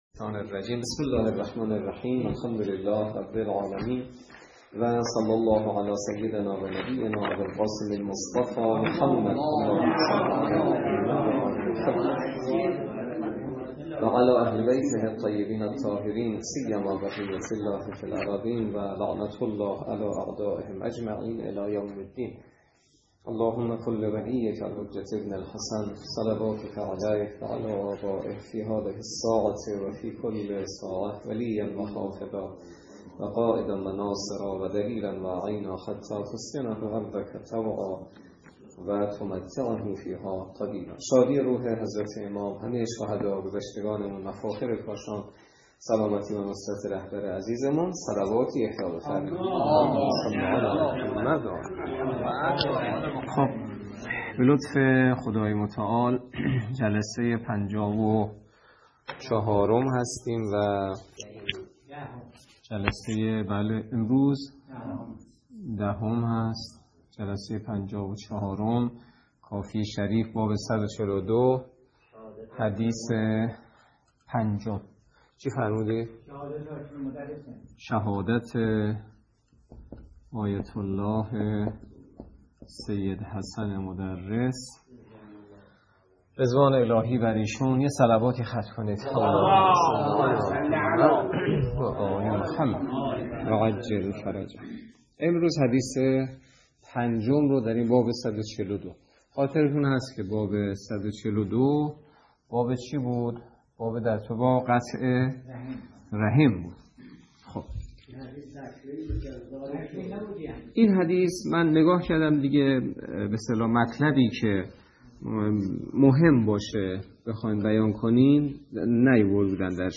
در محضر معصوم؛ با بیان حجت الاسلام والمسلمین سید سعید حسینی نماینده مقام معظم رهبری در منطقه و امام جمعه کاشان حدیث از: کافی باب ۱۴۲ (قطع رحم) حدیث ۵ ۱۰ آذر ماه ۱۴۰۳ Your browser does not support this audio دانلود